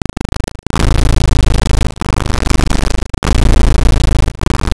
ミーの「ゴロゴロ」が聞けます。
＊　猫は、嬉しい時、安心している時にゴロゴロと喉を鳴らします。
喜んでゴロゴロいっているところを記録したものです。
gorogoro.wav